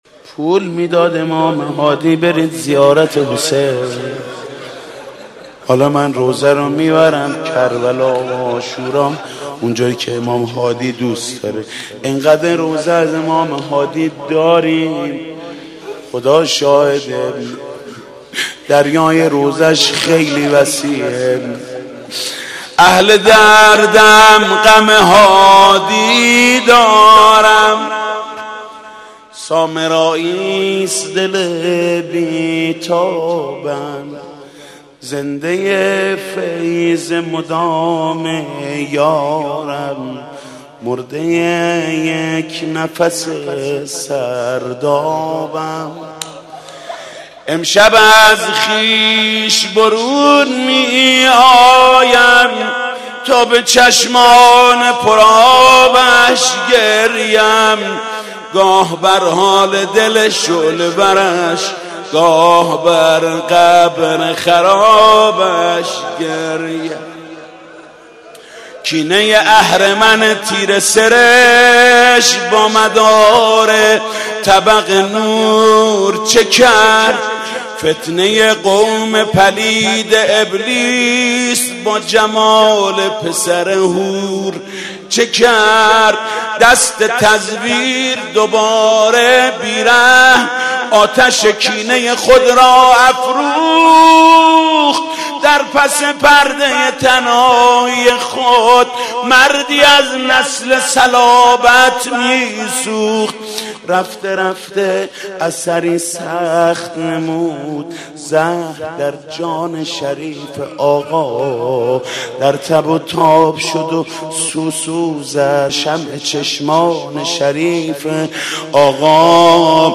نوحه های جدید نوحه سرایی و سینه زنی به مناسبت شهادت امام هادی (ع) هیت چهارده معصوم خمینی شهر(مکتب الحسین)فرا رسیدن سالروز شهادت حضرت امام هادی علیه السلام